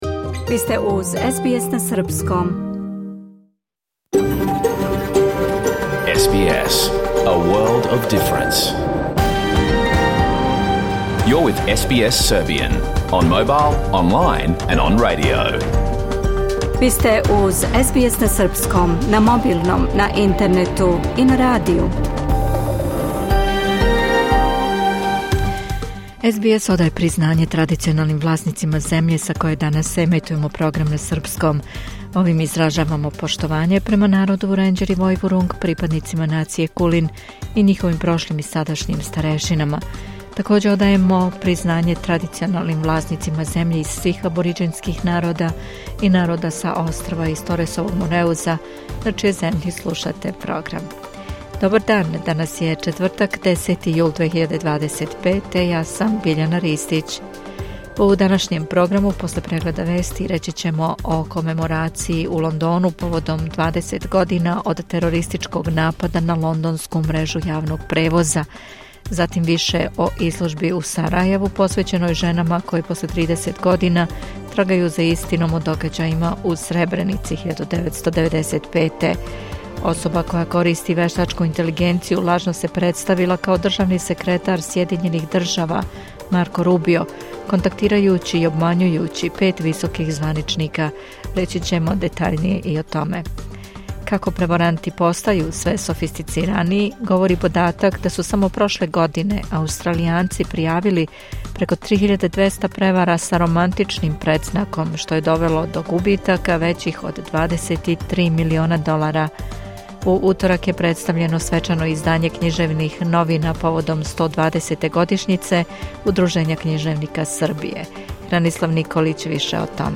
Програм емитован уживо 10. јула 2025. године